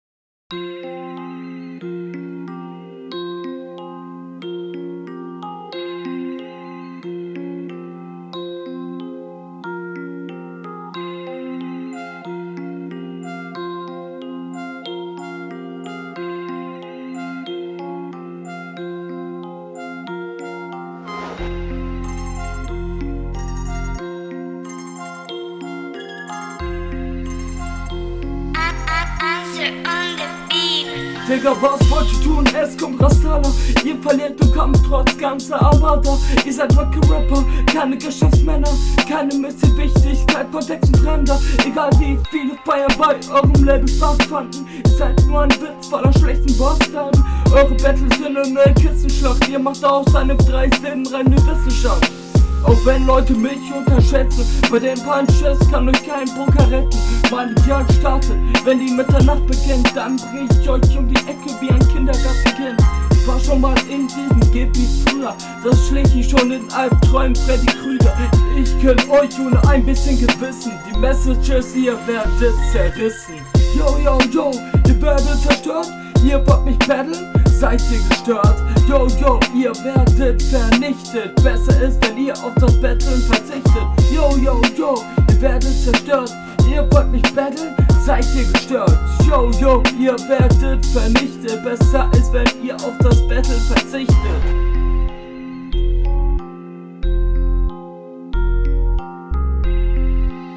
Battle Rap Bunker
Qualität nicht geil bin aber stehts bemüht.